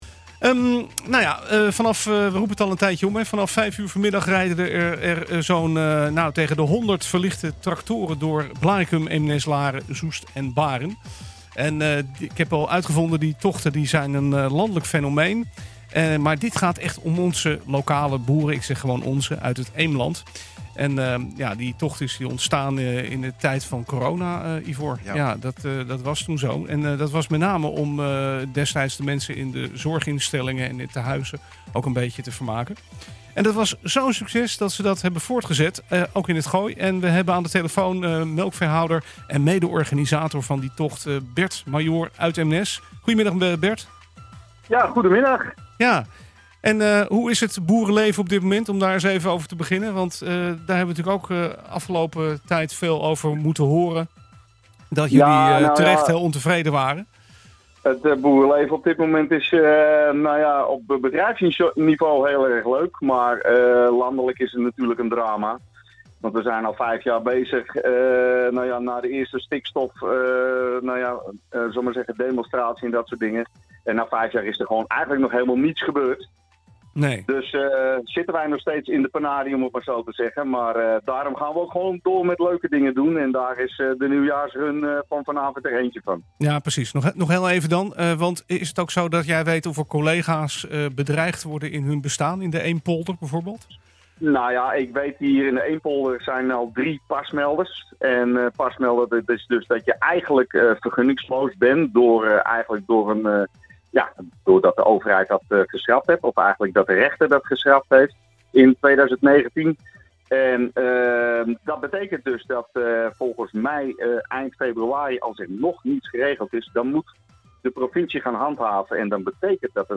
Aan de telefoon